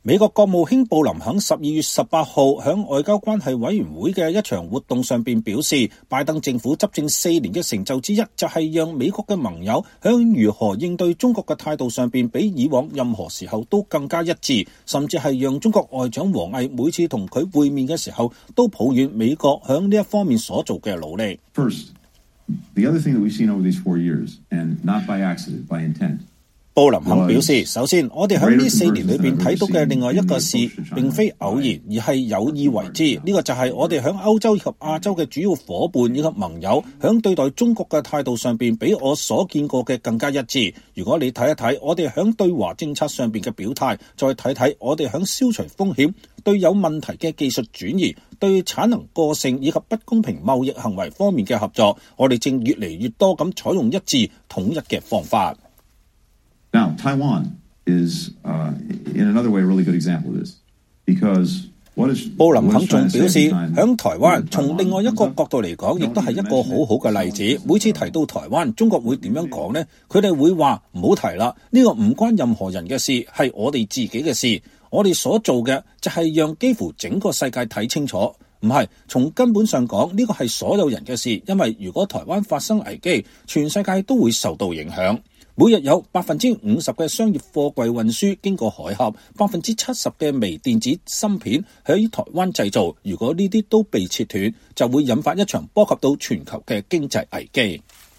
美國國務卿布林肯12月18日在外交關系會的一場活動上說，拜登政府執政四年的成就之一，就是讓美國的盟友在如何應對中國的態度上比以往任何時候都更加一致，甚至讓中國外長王毅每次和他會面時都抱怨美國在這方面所做的努力。